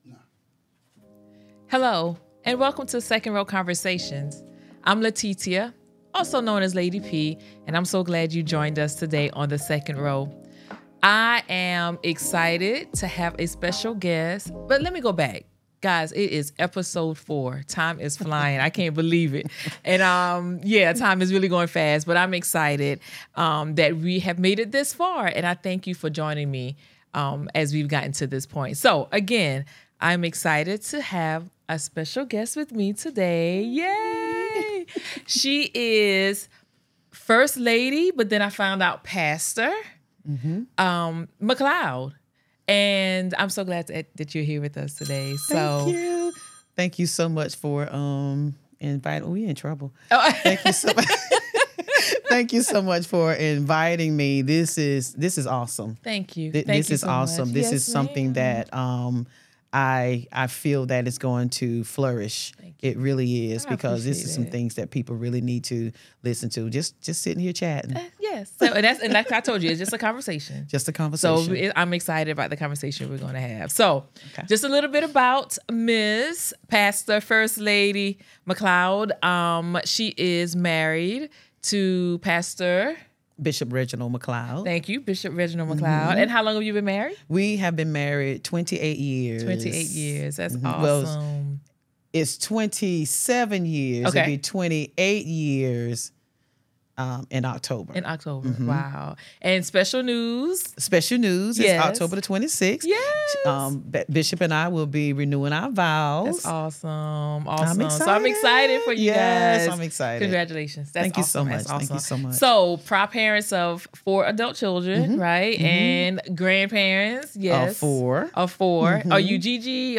They have an enlightening conversation about living a life that represents Christ everywhere they go and the importance of setting boundaries.